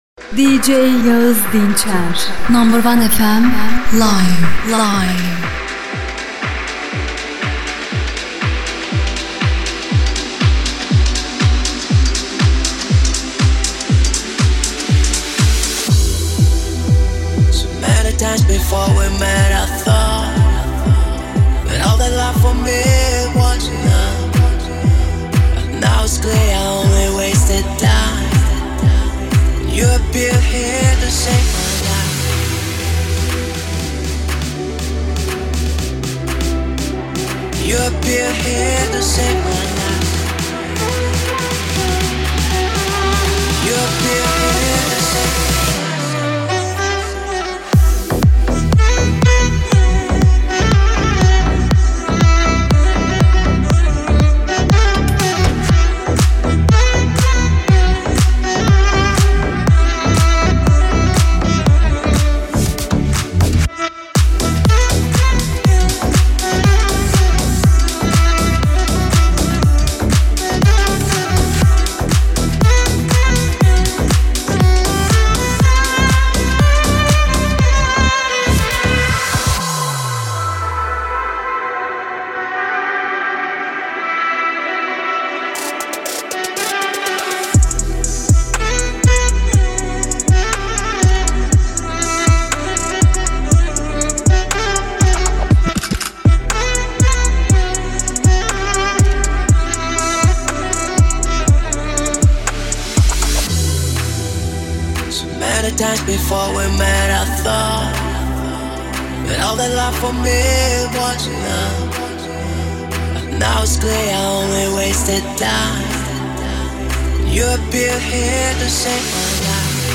DJ Set